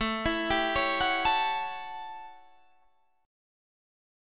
Listen to A13 strummed